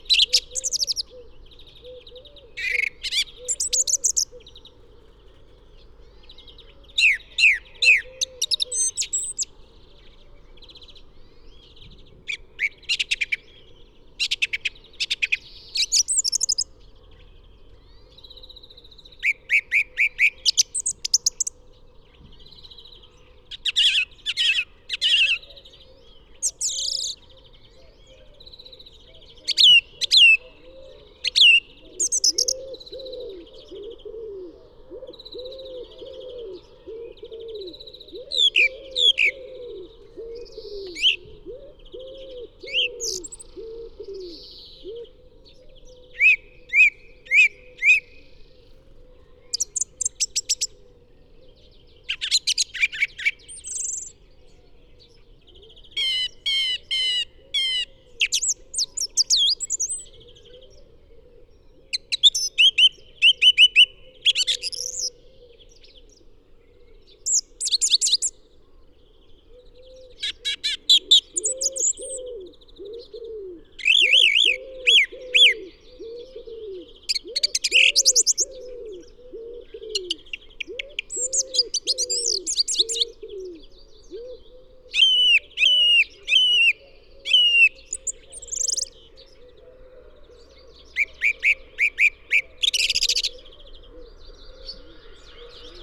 lijster.mp3